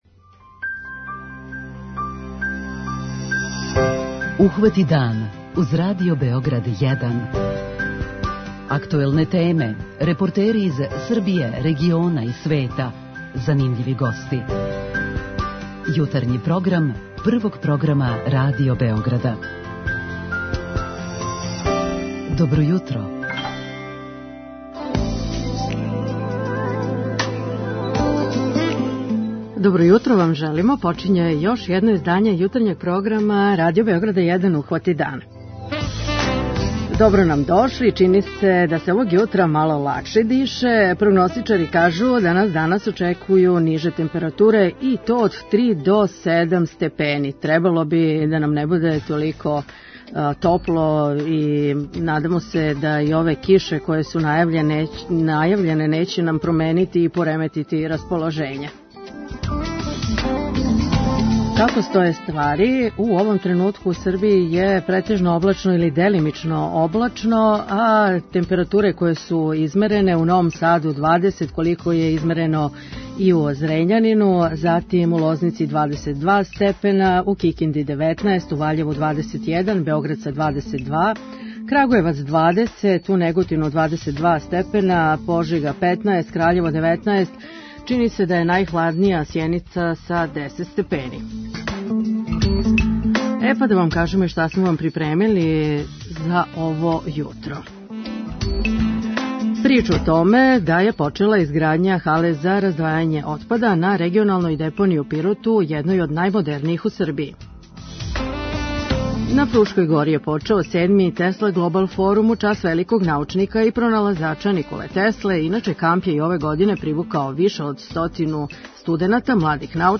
преузми : 37.77 MB Ухвати дан Autor: Група аутора Јутарњи програм Радио Београда 1!